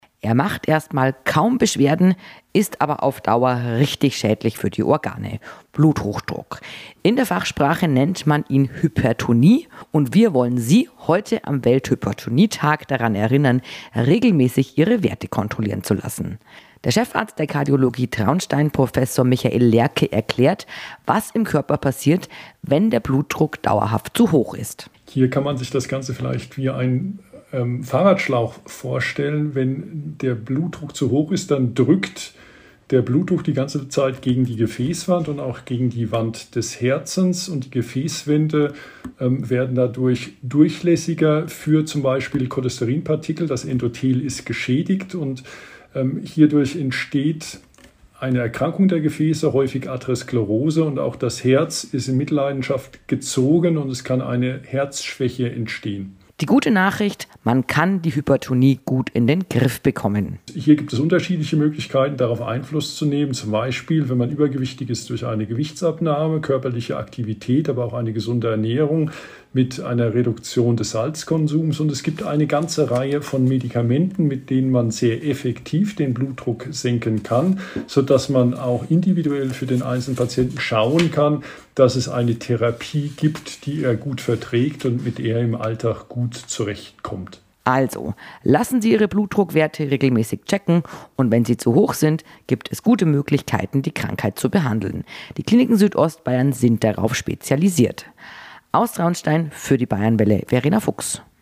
Im Format „Gesundheit KOMPAKT“ mit der Bayernwelle Südost sprechen Mediziner, Therapeuten und Pflegekräfte über medizinische Themen oder Aktuelles aus den Kliniken Südostbayern AG.